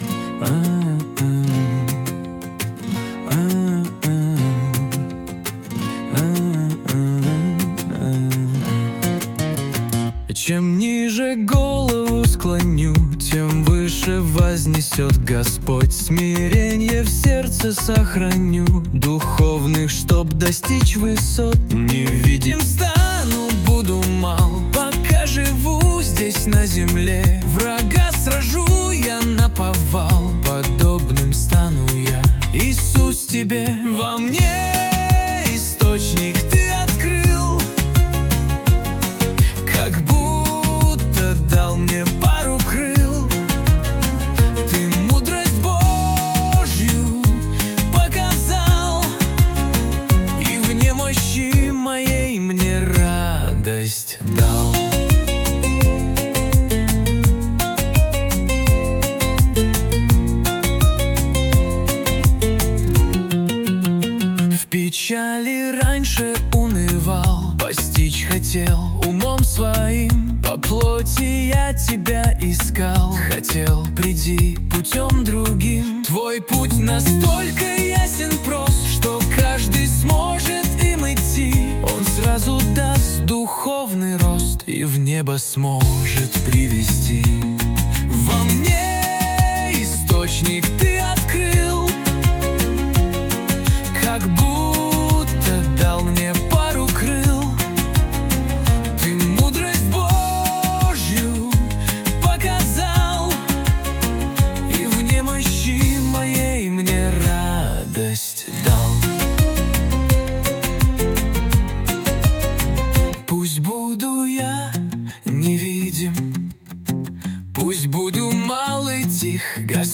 песня ai
169 просмотров 980 прослушиваний 74 скачивания BPM: 169